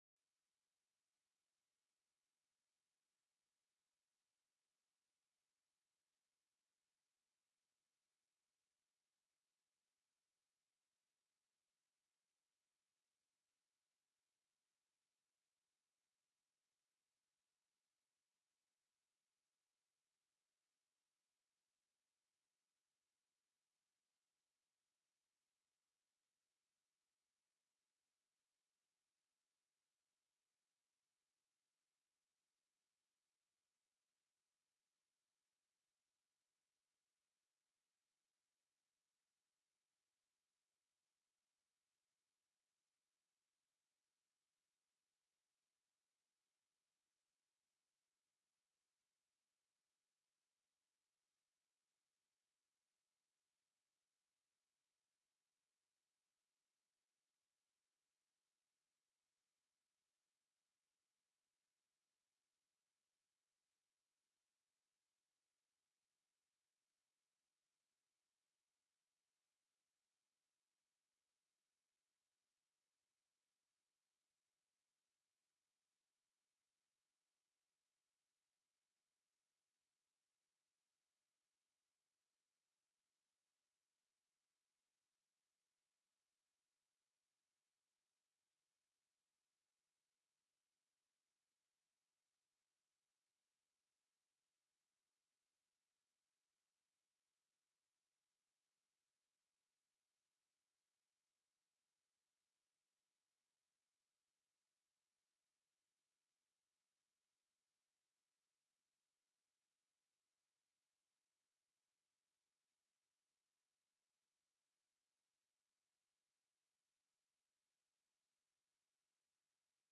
The audio recordings are captured by our records offices as the official record of the meeting and will have more accurate timestamps.
Overview: Alaska Marine Highway System TELECONFERENCED